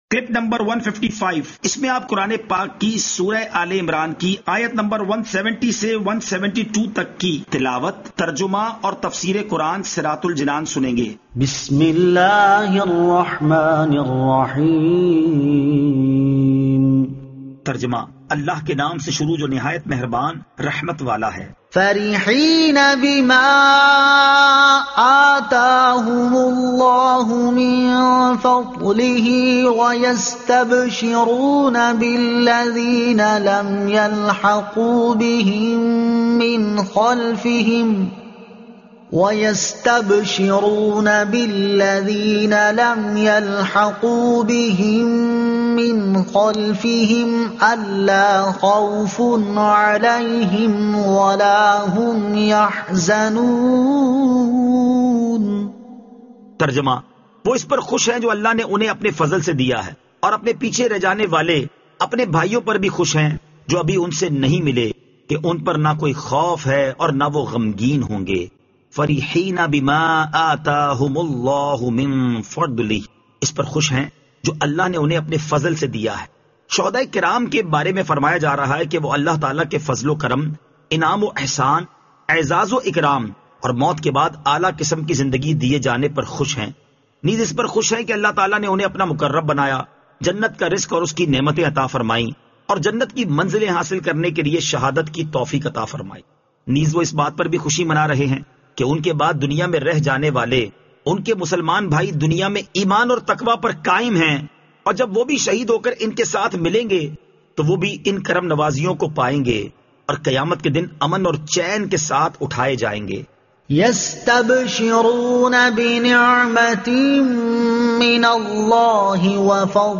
Surah Aal-e-Imran Ayat 170 To 172 Tilawat , Tarjuma , Tafseer